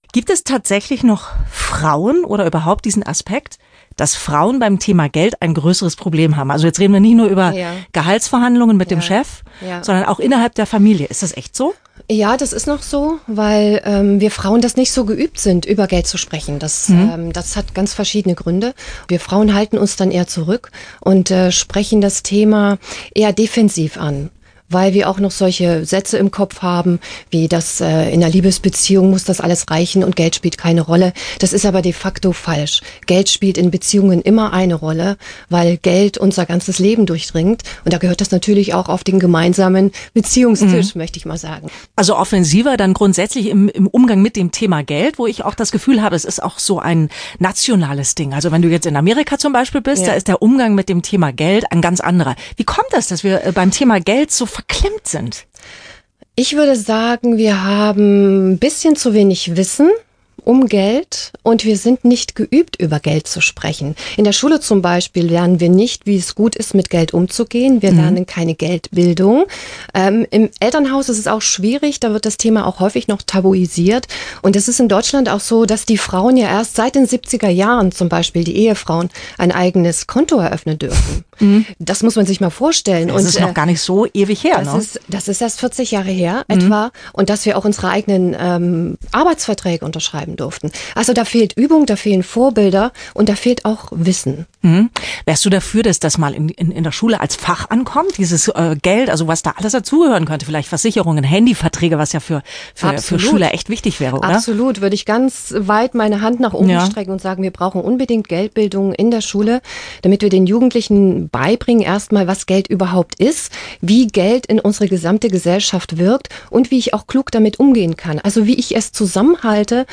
Tür zu. Mikros auf.
Der oder die impulsgebende Expert’in ist meist aufgezeichnet, so wie das bei mir war.